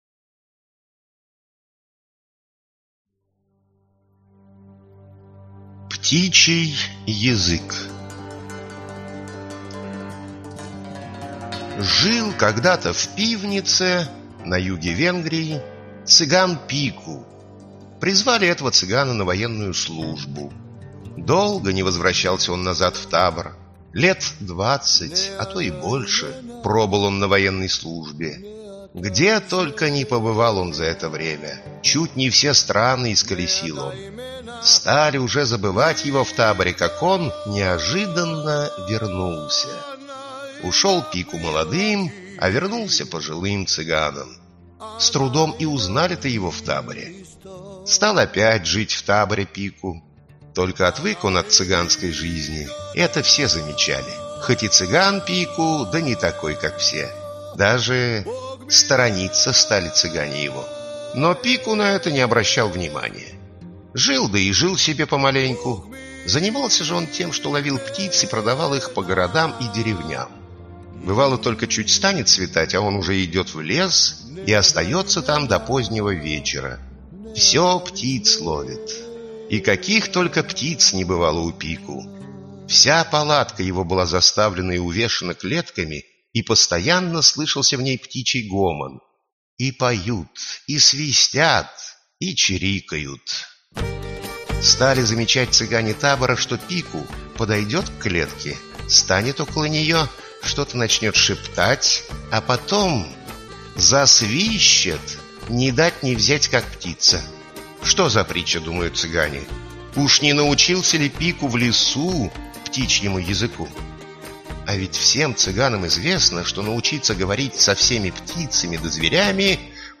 Аудиокнига Цыганские народные сказки | Библиотека аудиокниг